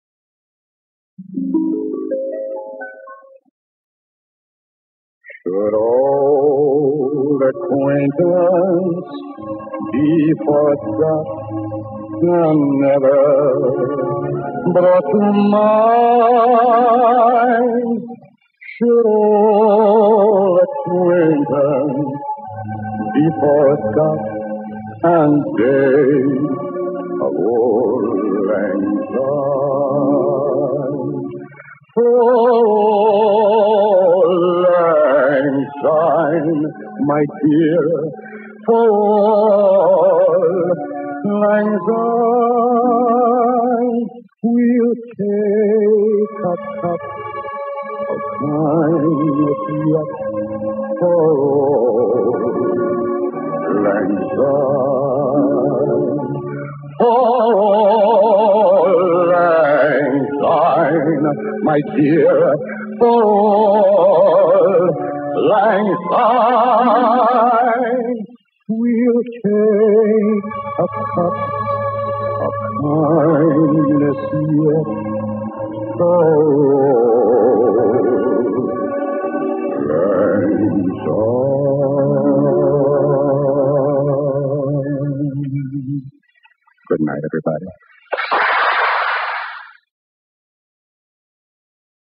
A Scots-language poem written by Robert Burns in 1788 and set to the tune of a traditional folk song